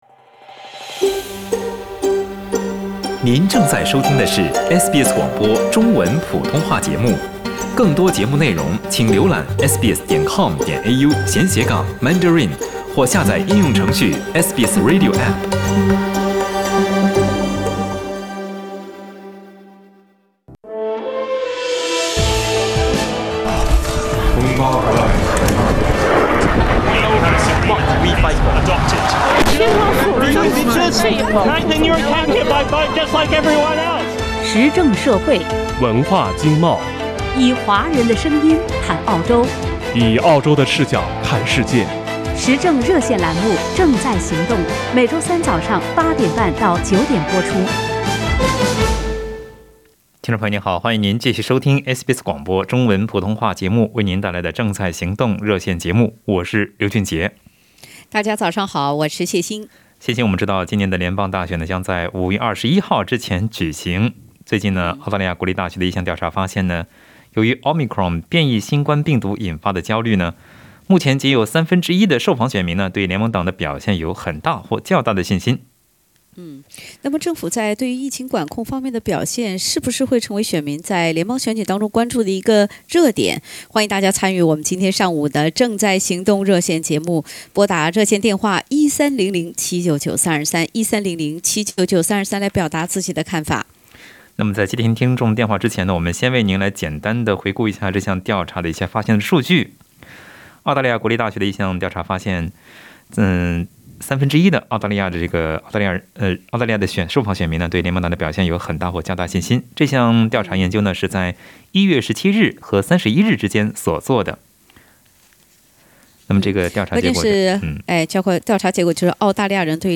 在本期《正在行动》热线节目中，听友们就政府管控新冠疫情表现是否会影响联邦选举发表了看法，请您点击收听详细内容。